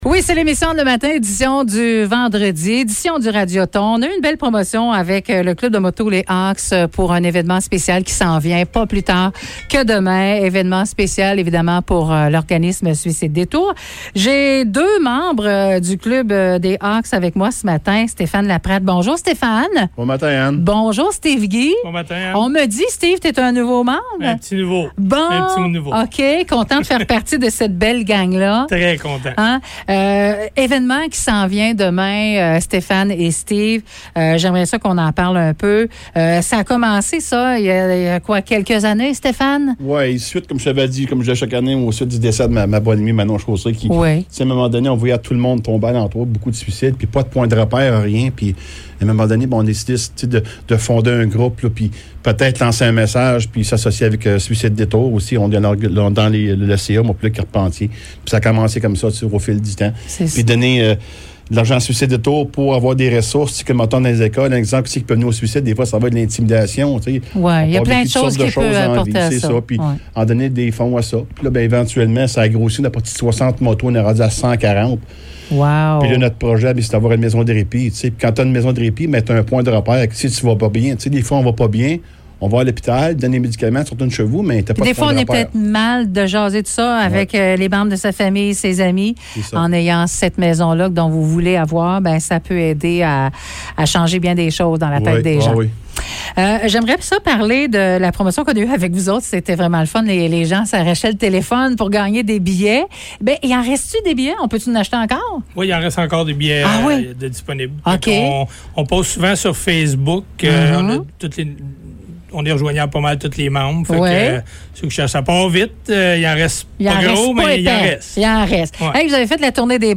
entrevue-avec-les-hawks.mp3